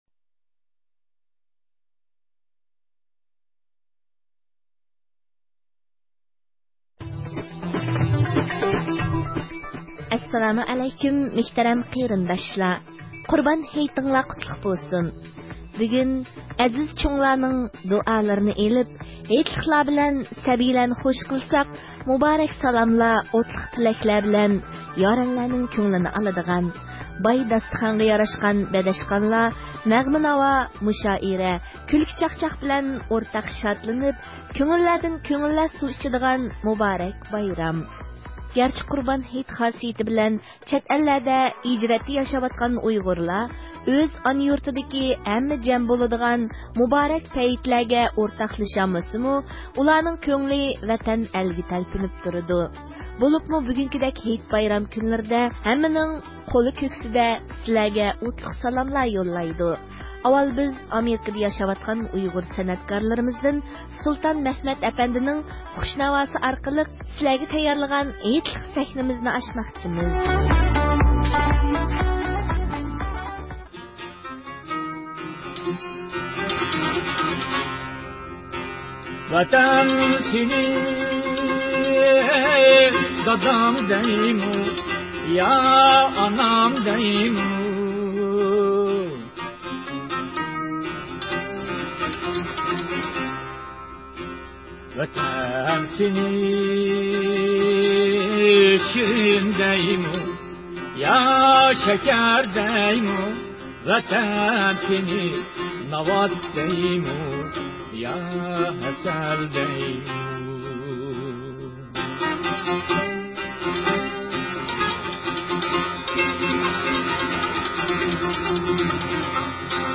ئانا ۋەتەن ھەققىدە سېغىنىشلىق كۈيلەر، ھىجرەتتىكى ئۇيغۇرلارنىڭ ئورتاق ناخشىسى، ئەل بىلەن تەڭ بايرام ئۆتكۈزۈش ھەممىنىڭ ئورتاق ئارمىنى.